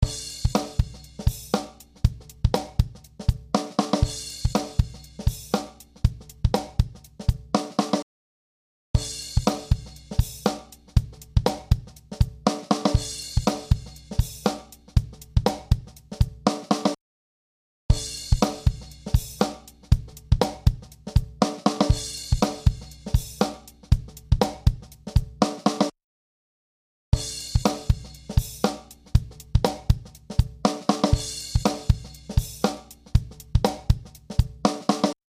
アナログコンソール特有の暖かさや、太さ、歪みを追加できる。
それぞれ違った個性の３種類のコンソールがシミュレートされていて、サウンドに深みを与えてくれる。
音は上品な温かみを加味してくれる感じ。
音は一番個性的で懐かしい感じの音がする。
３機種の中では一番おとなしい感じの音だが、音圧が出ているような印象。